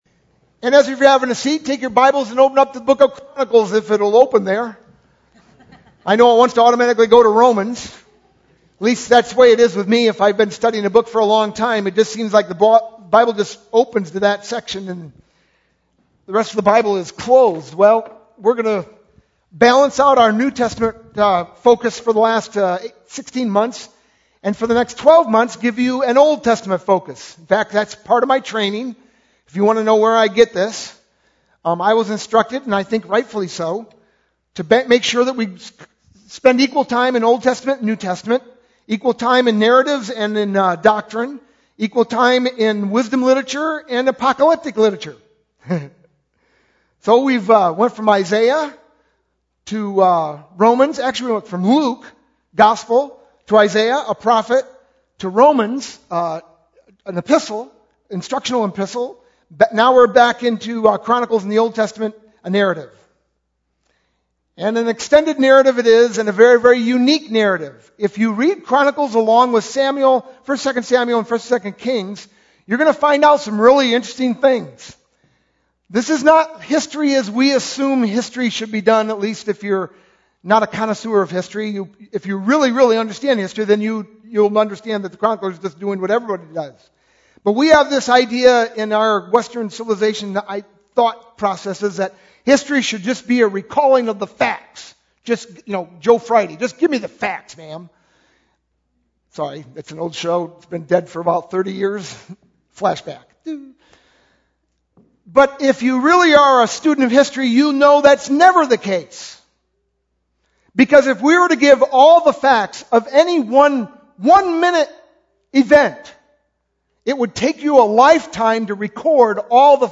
sermon-4-15-12.mp3